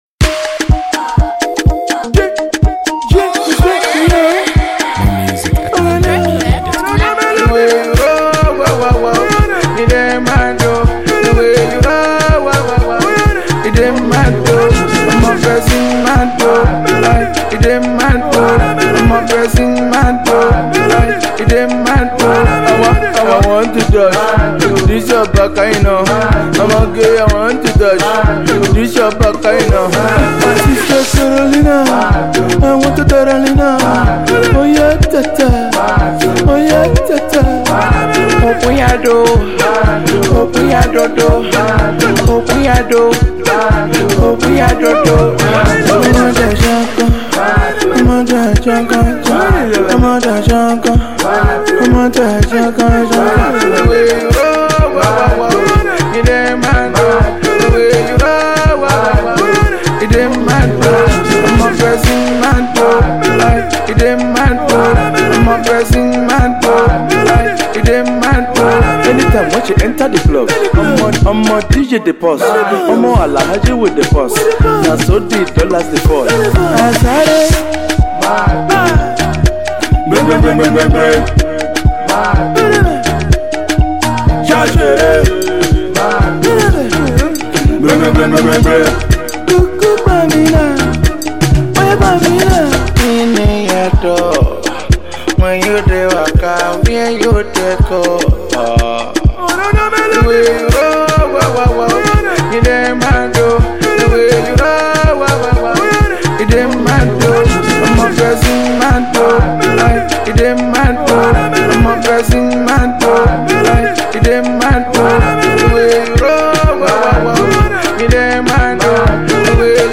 Genre: Afrobeats
dance hall tune